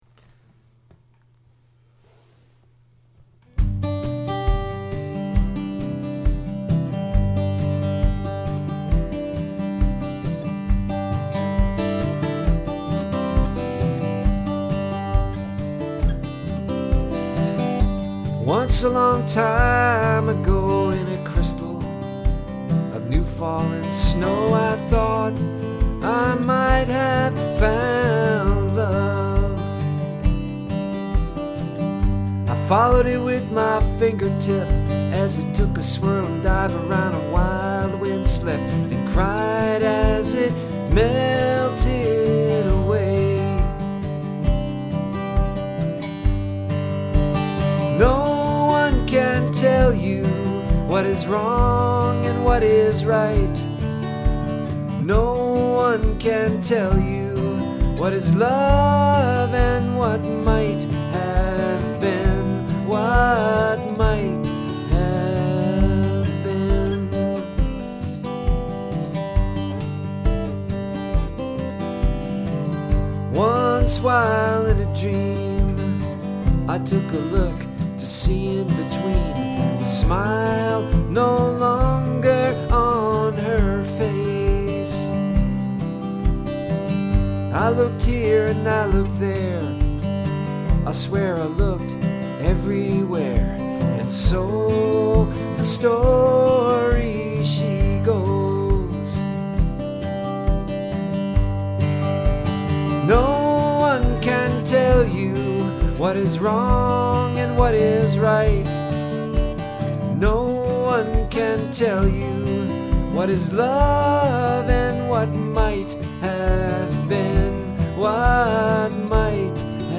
Of course I was singing to my fish, and I am not my cousin who recorded New Fallen Snow professionally.